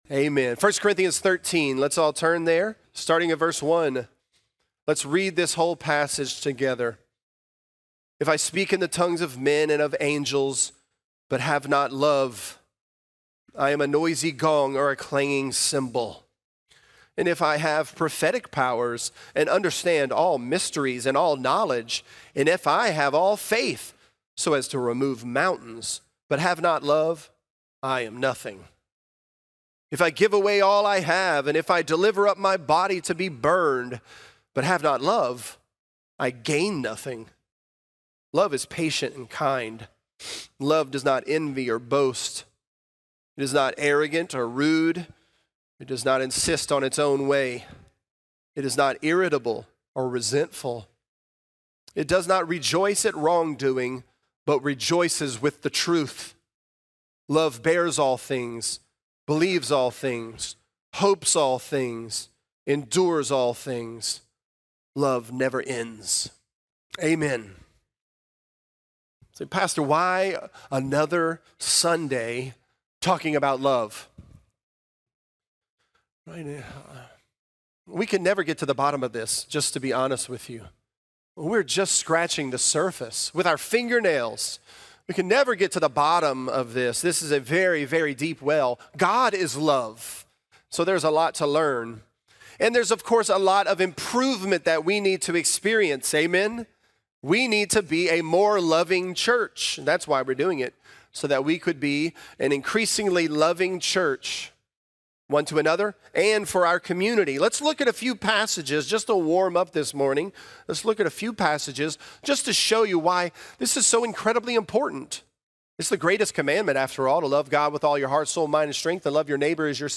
This is a part of our sermon series.